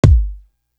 Book Of Rhymes Kick.wav